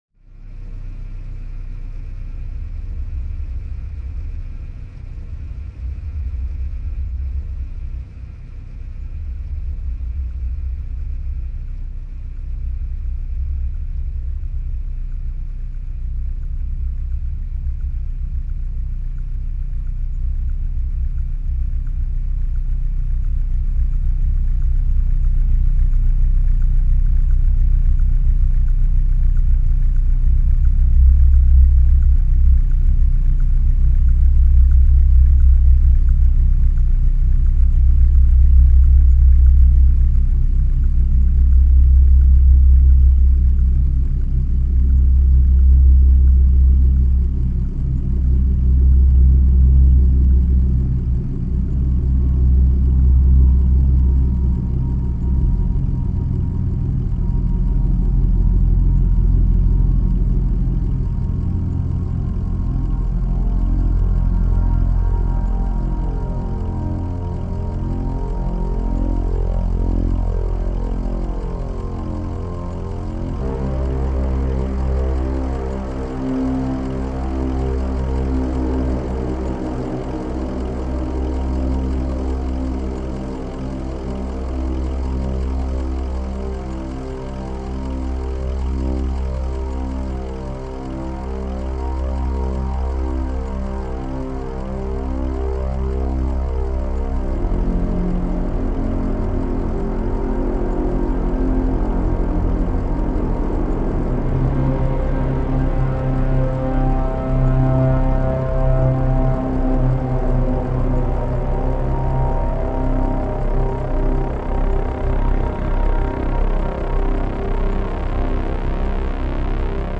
Les éléments musicaux émergent lentement du Sound Design (ici, comme si on suivait un personnage qui avance lentement entre des voitures).
Intensité Haute